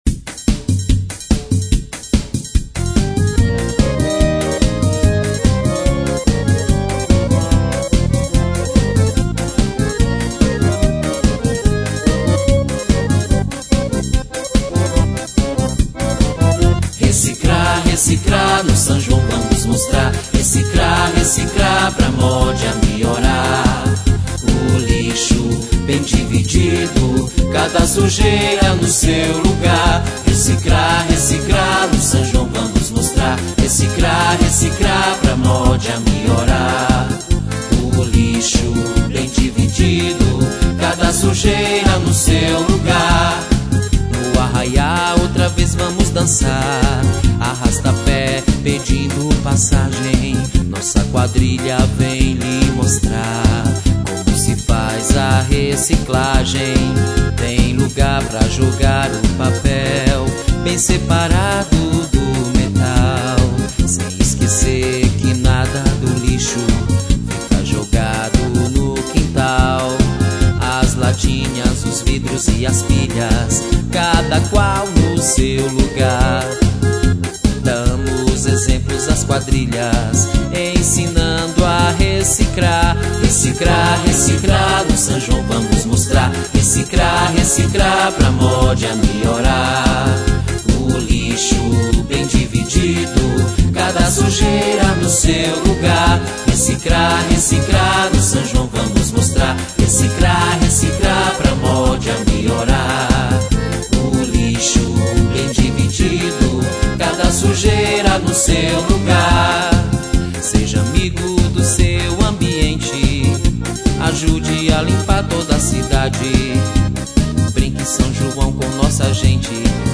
Festa São João da Sociedade de Assistência aos Cegos